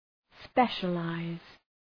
Προφορά
{‘speʃə,laız}